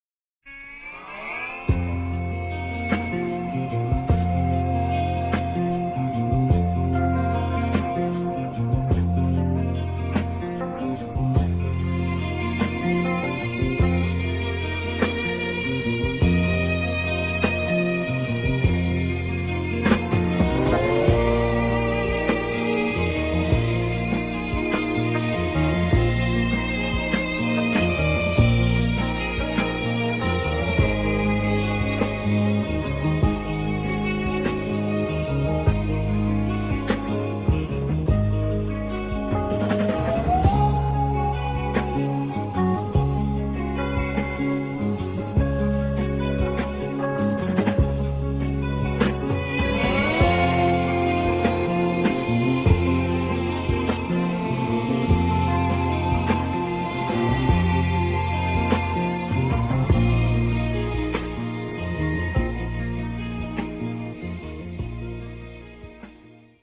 stereo, 4.0 Khz, 20 Kbps, file size: 164 Kb